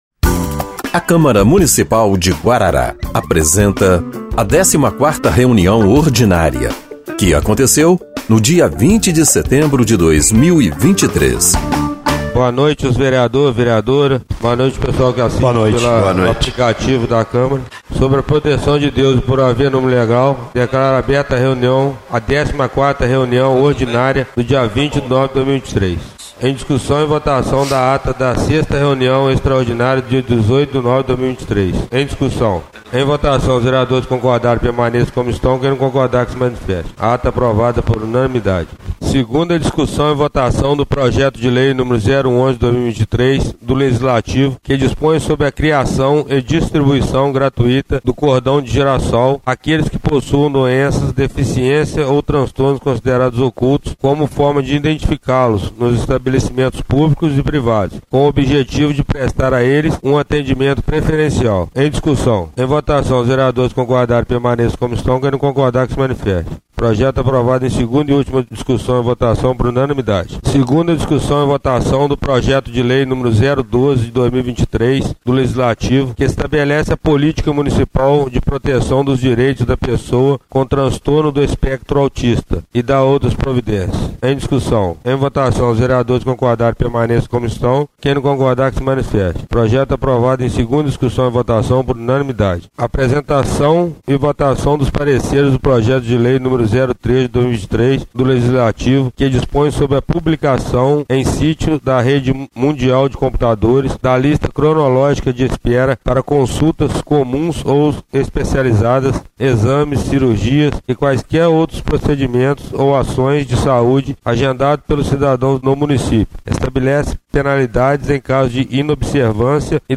14ª Reunião Ordinária de 20/09/2023